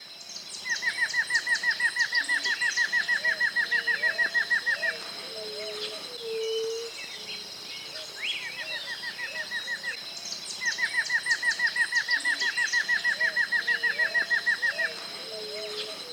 Voz
choca-corona-negruzca_voz.mp3